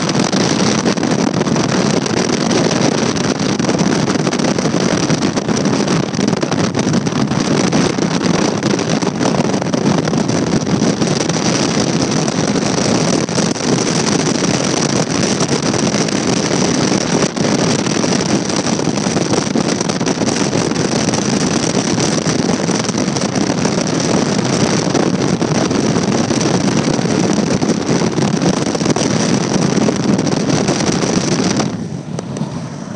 Wind from an open car window is a car is driving